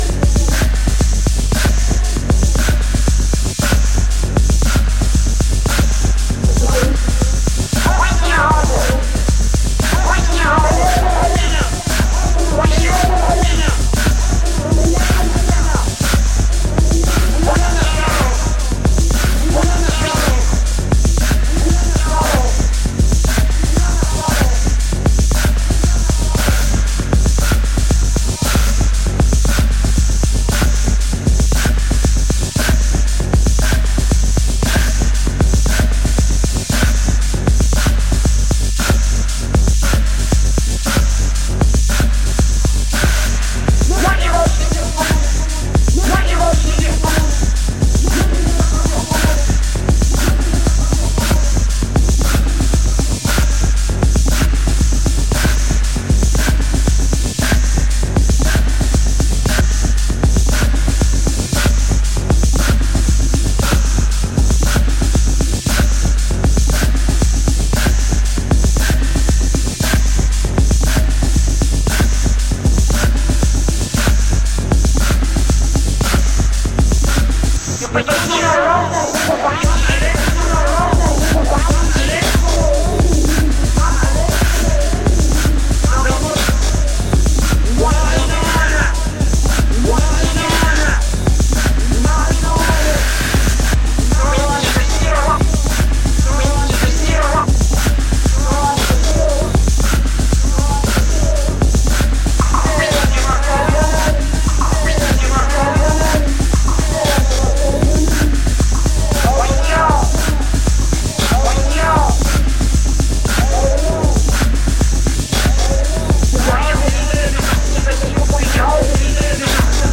EBM/Industrial